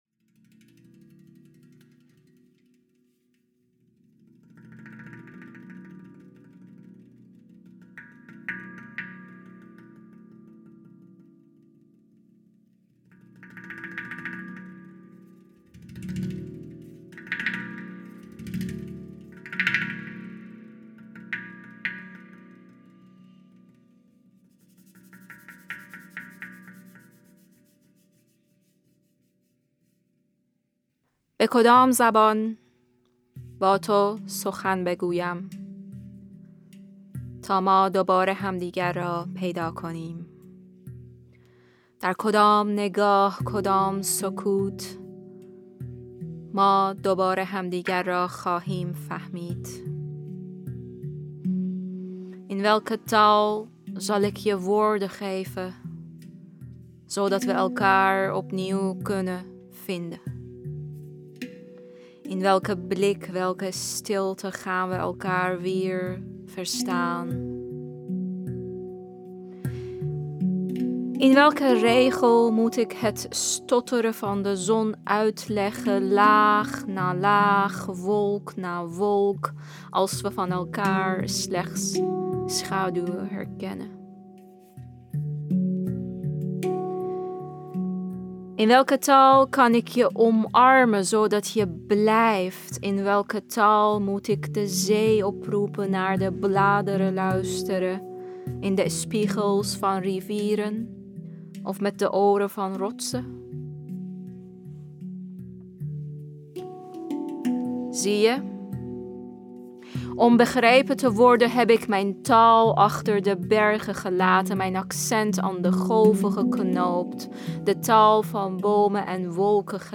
Ambo|Anthos uitgevers - Neem ruim zei de zee luisterboek